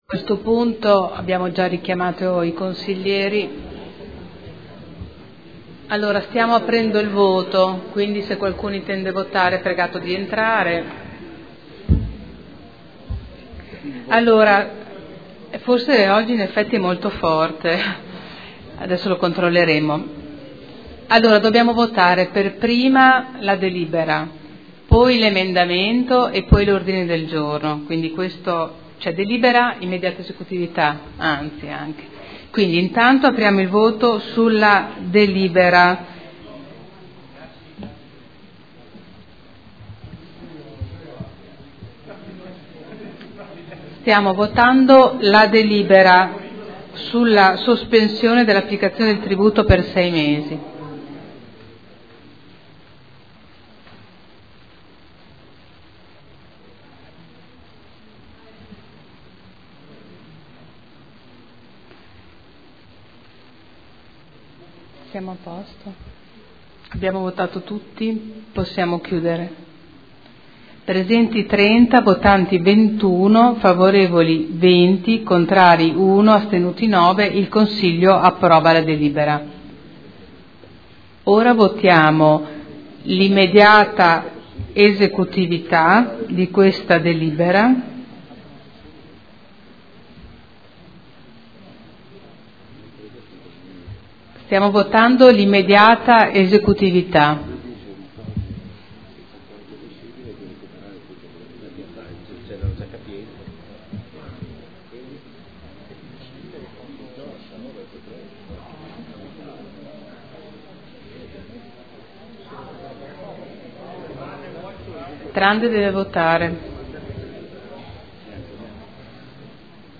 Presidente — Sito Audio Consiglio Comunale
Seduta del 17/12/2012.